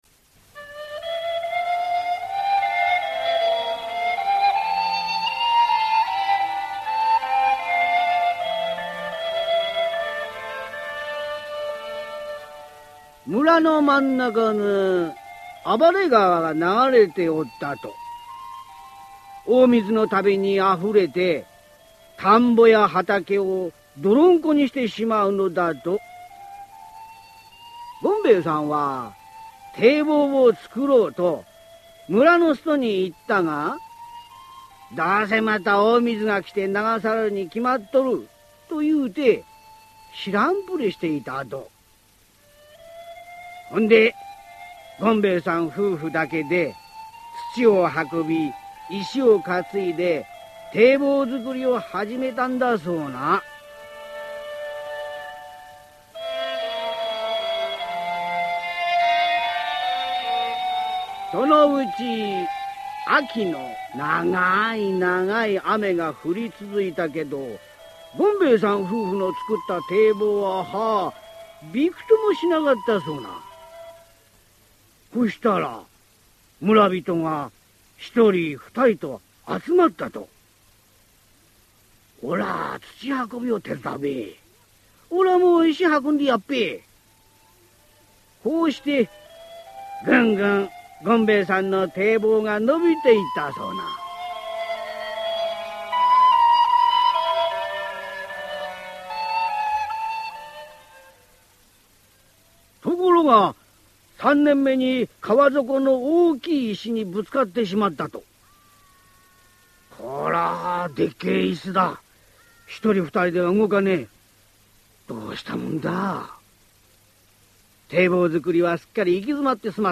[オーディオブック] 丈六地蔵